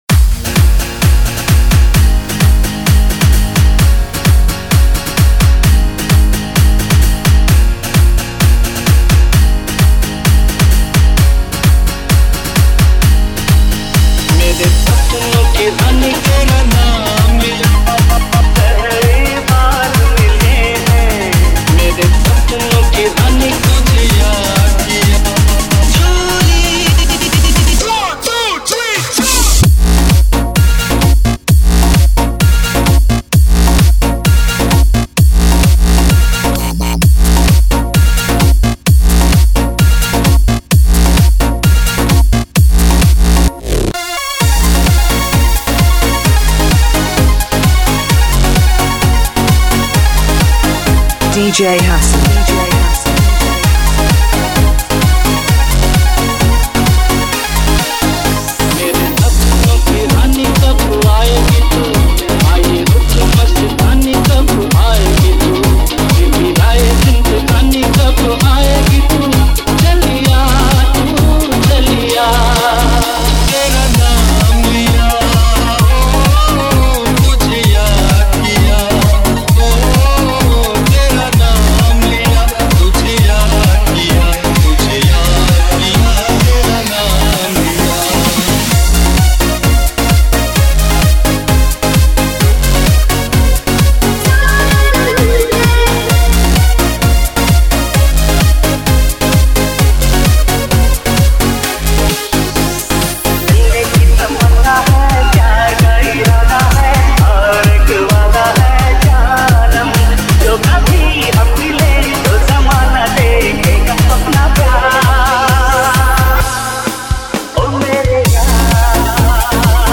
HomeMp3 Audio Songs > Others > Single Dj Mixes